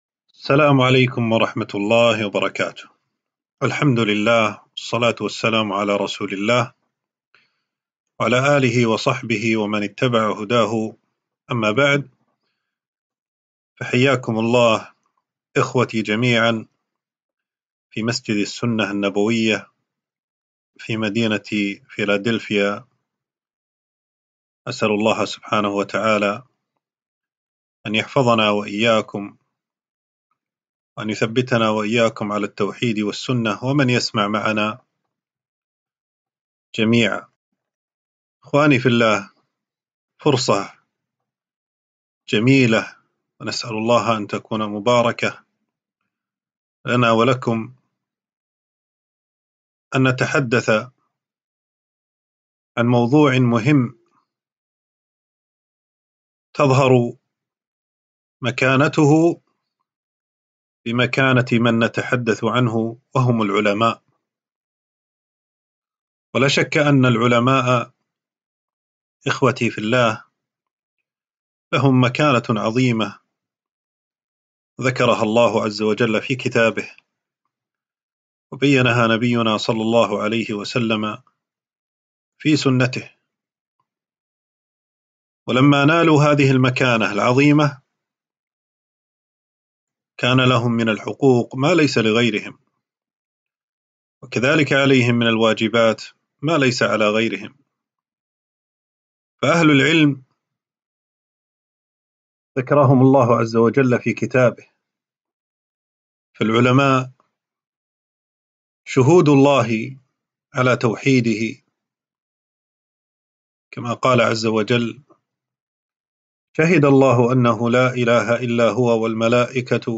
محاضرة - فضل العلماء